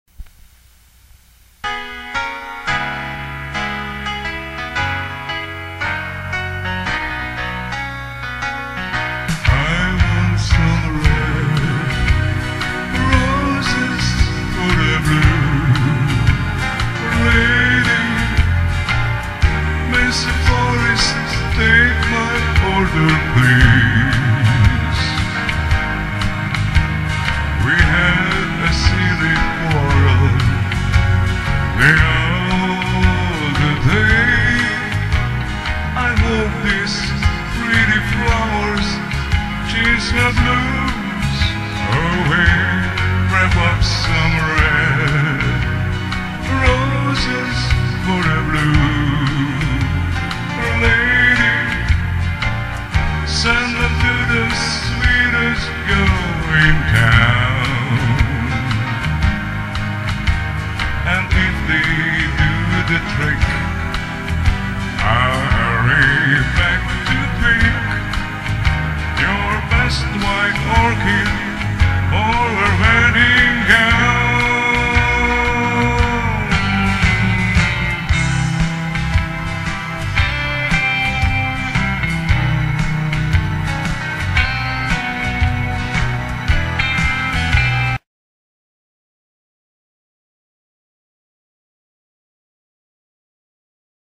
guitar
Easy Listening